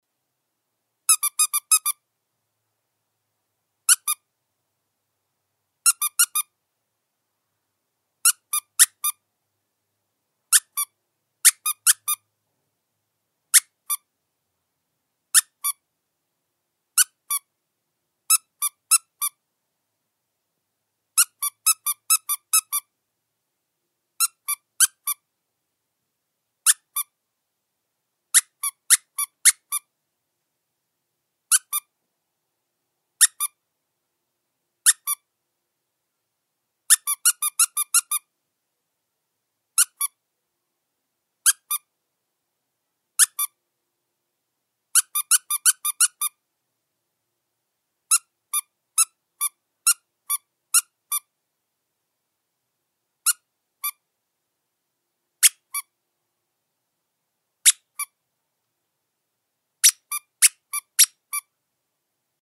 Звуки игрушек для собак
Последний вариант пищалки